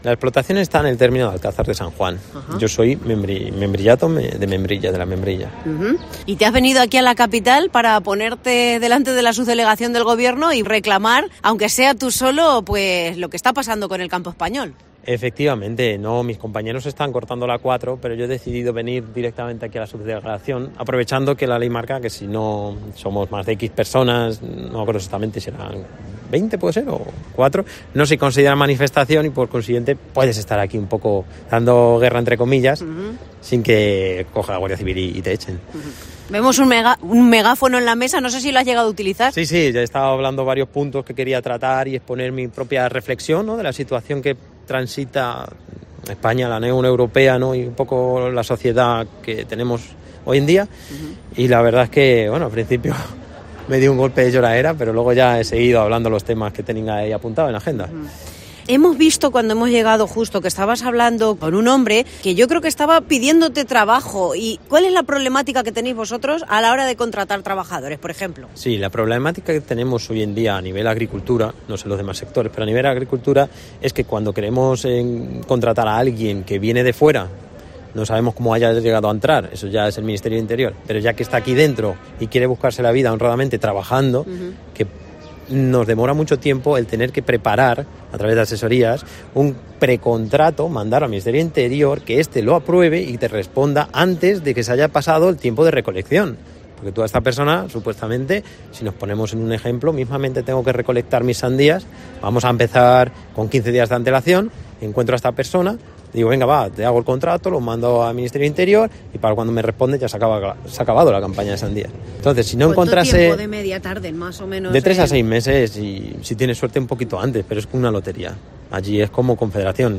Con una mesa plegable, un megáfono y una pancarta en la que podía leerse "¿Para qué tantas regulaciones e inspecciones en producir alimentos de calidad si luego NO se prioriza el CONSUMO de estos?".